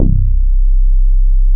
MIDDLE BASS.wav